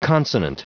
Prononciation du mot consonant en anglais (fichier audio)
Prononciation du mot : consonant